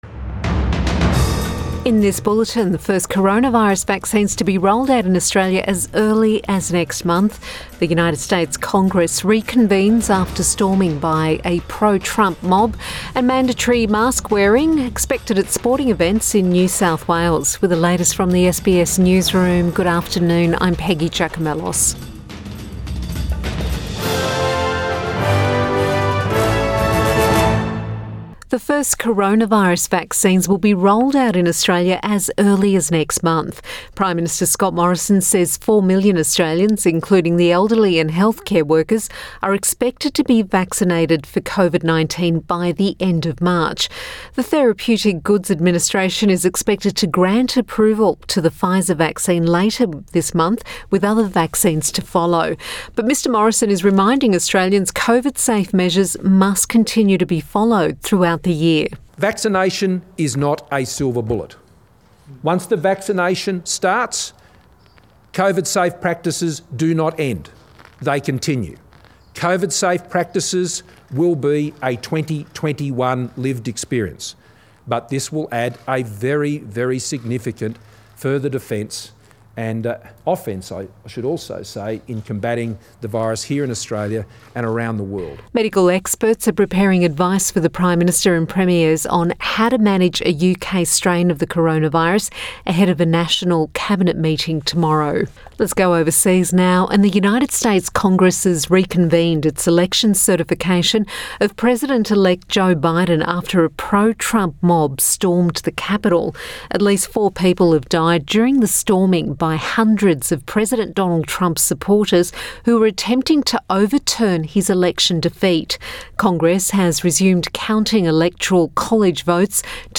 PM bulletin 7 January 2021